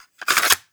REMOVE_CASSETTE_07.wav